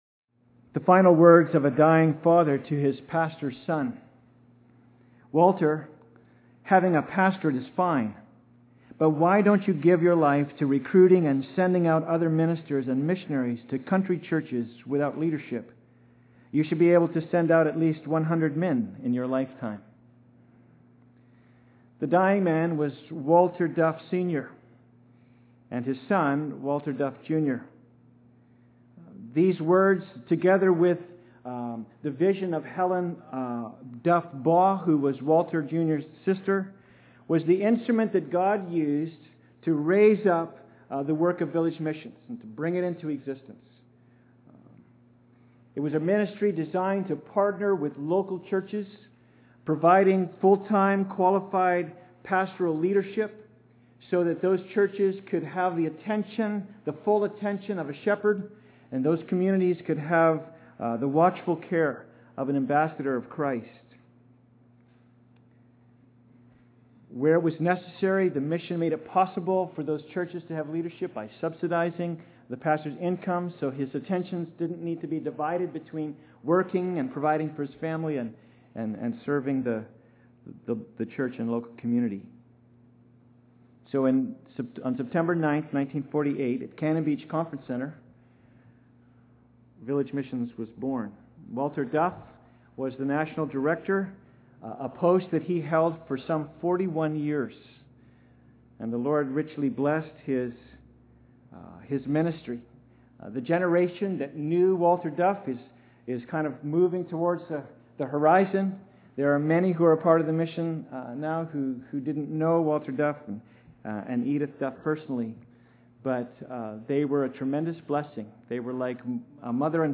Service Type: Sunday Service
Sermon_10.26.m4a